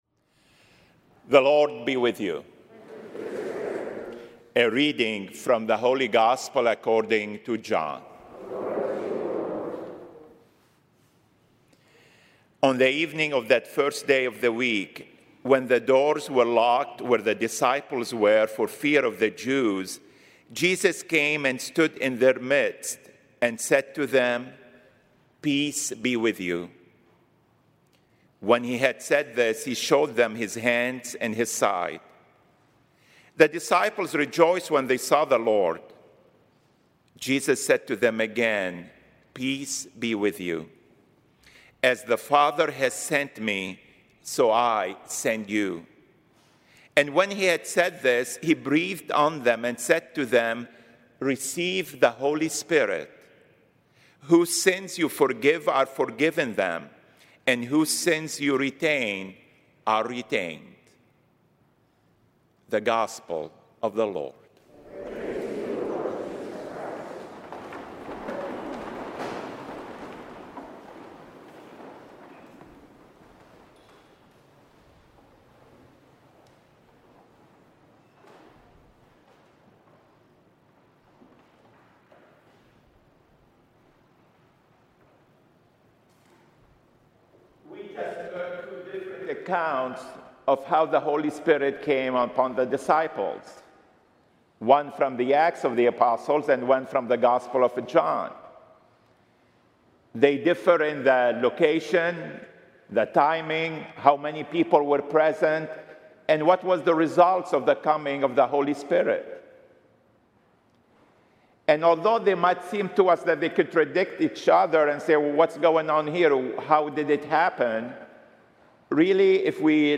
Solemnity of Pentecost Sunday, June 5, 2022, 9:30 Mass